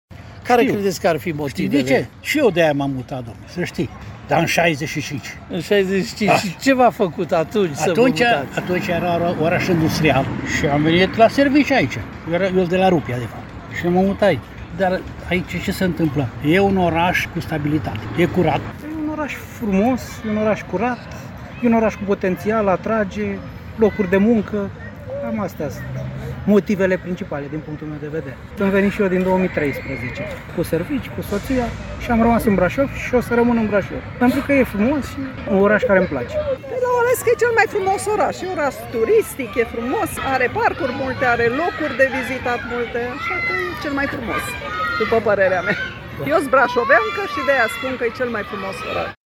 Locuitorii mai noi sau mai vechi ai orașului dezvăluie motivele pentru care cred ei că Brașovul este preferat de atât de mulți români.
vox-brasov.mp3